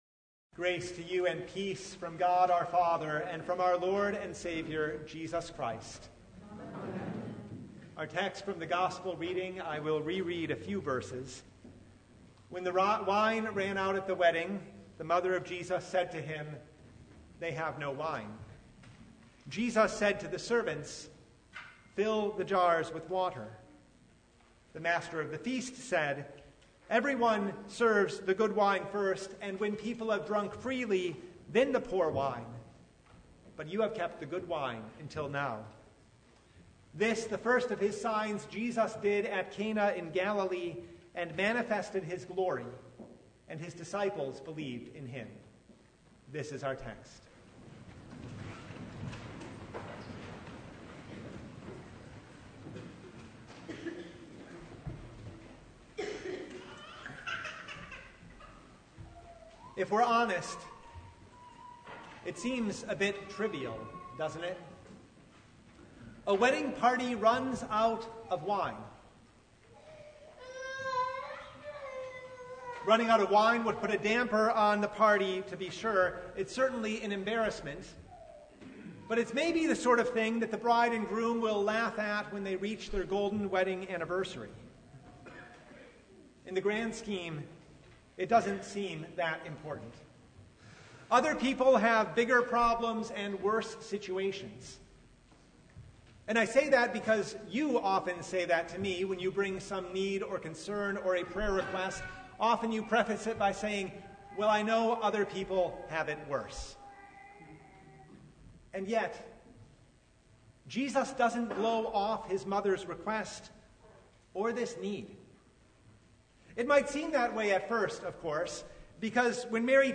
John 2:1–11 Service Type: Sunday Changing water into wine for a wedding feast?